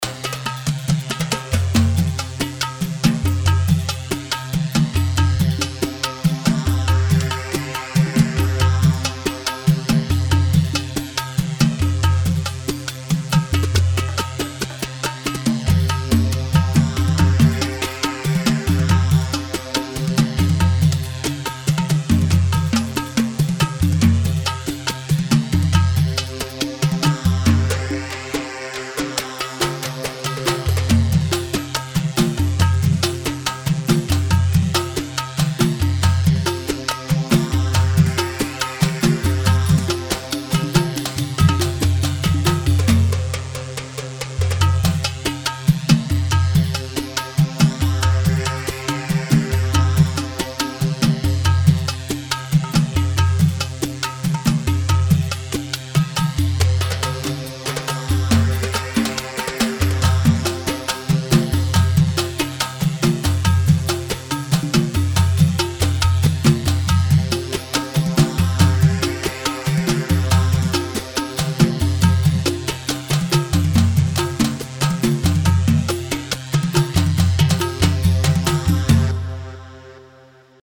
Rhumba 4/4 70 رومبا
Rhumba-70-4-4-mix.mp3